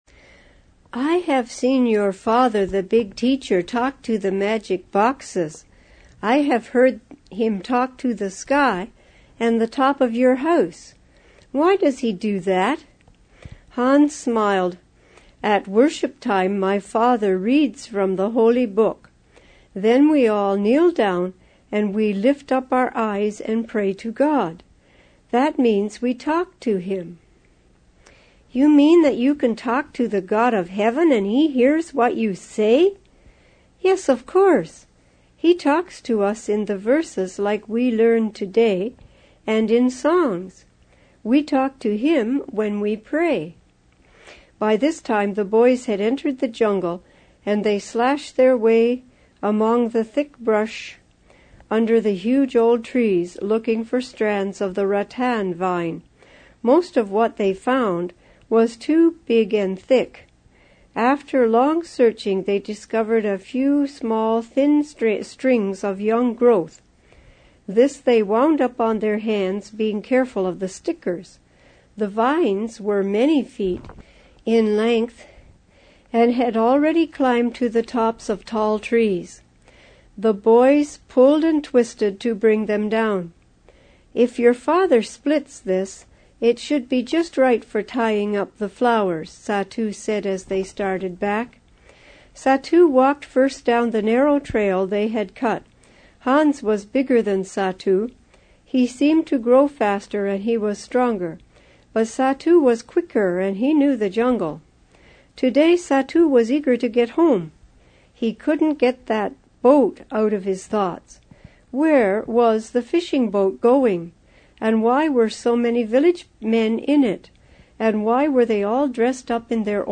Granny Reads
An Exciting True Mission Story Book in Audio MP3